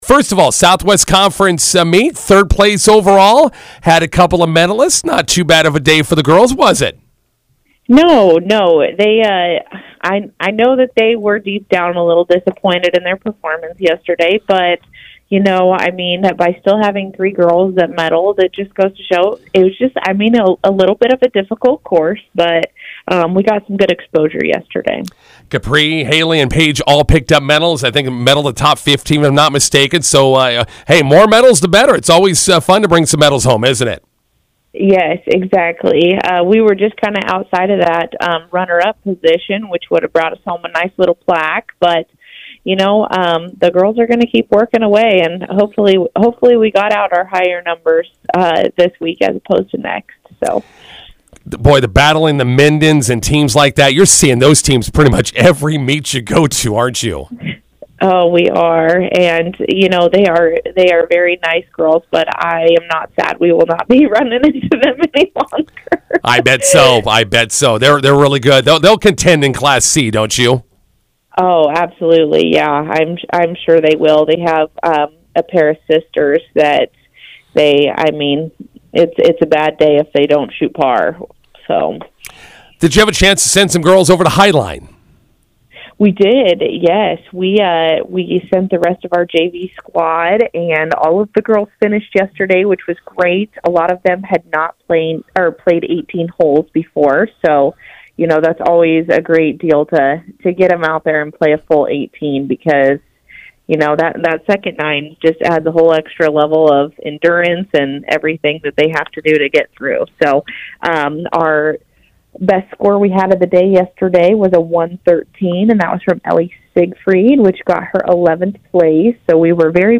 INTERVIEW: Lady Bison golfers finish third at SWC, prepare for district meet in York on Monday.